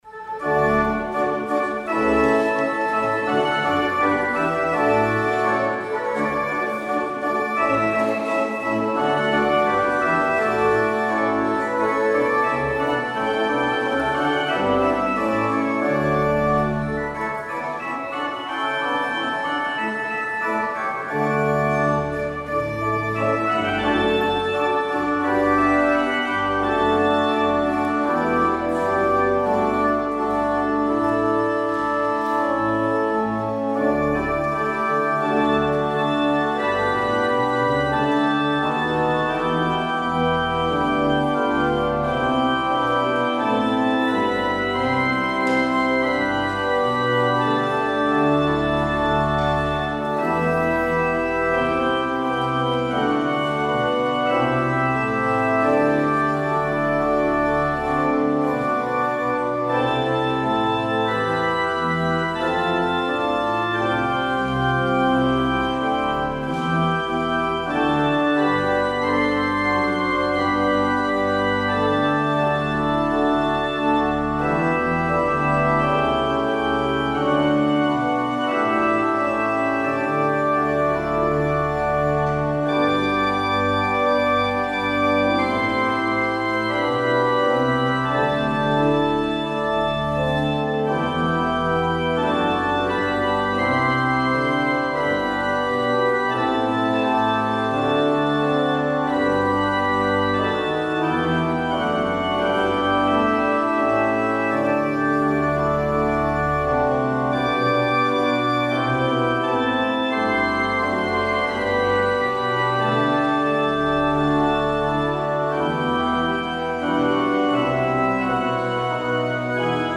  Luister deze kerkdienst terug: Alle-Dag-Kerk 25 januari 2022 Alle-Dag-Kerk https